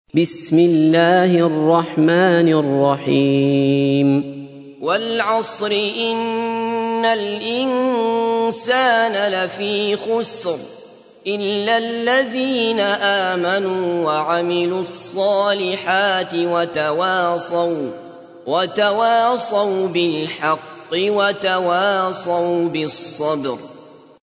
تحميل : 103. سورة العصر / القارئ عبد الله بصفر / القرآن الكريم / موقع يا حسين